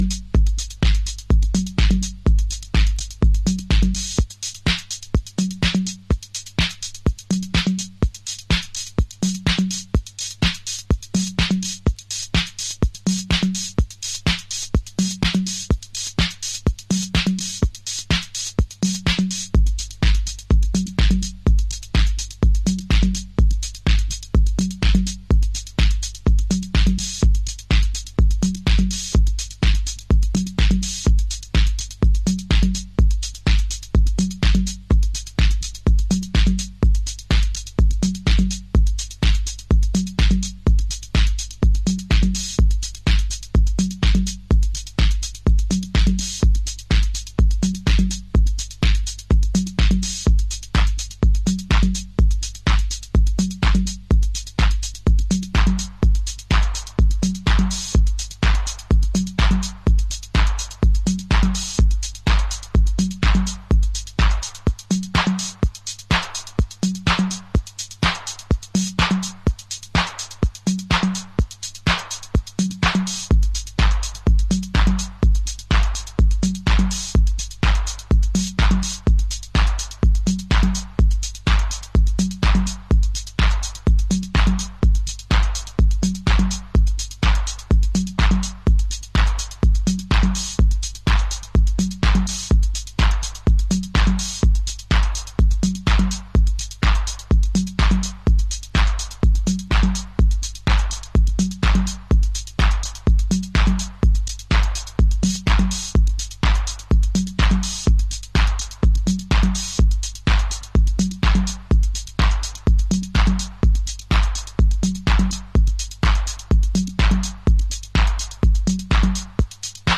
House / Techno
ピュア TR808 & TB303
今回も痛快なピュアアシッドハウス